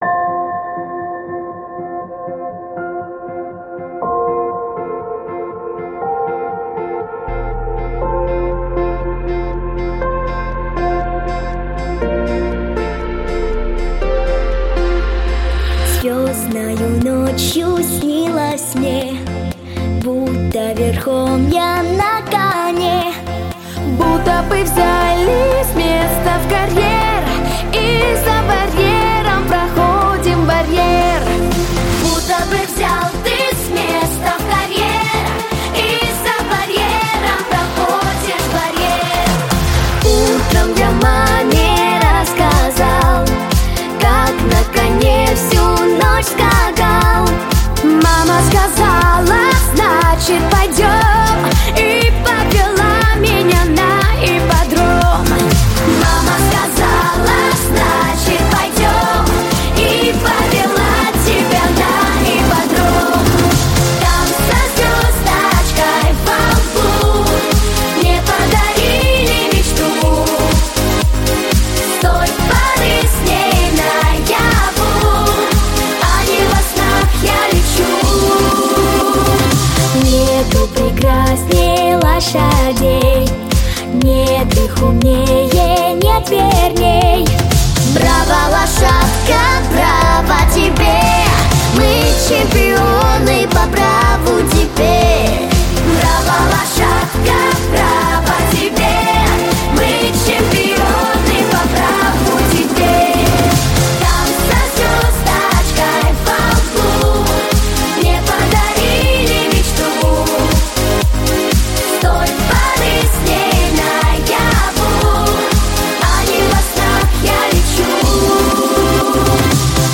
• Качество: Хорошее
• Жанр: Детские песни
Детская песня